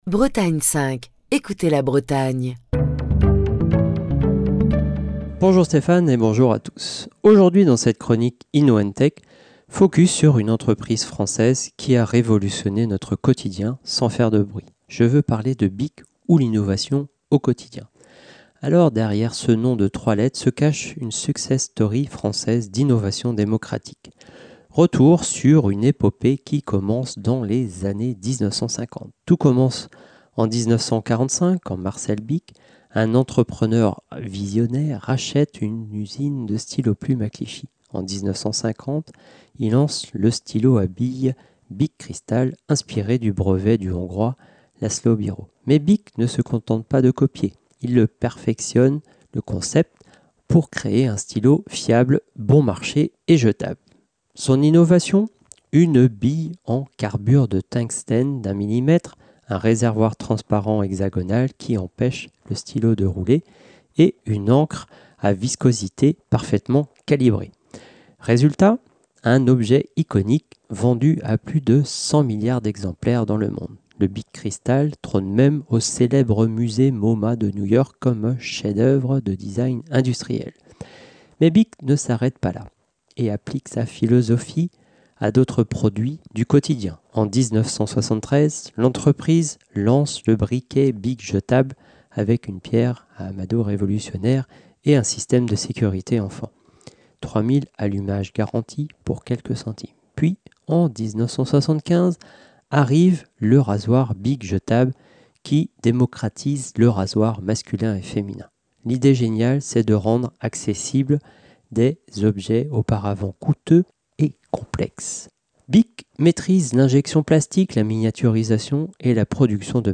Chronique du 17 décembre 2025.